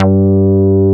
P MOOG G3F.wav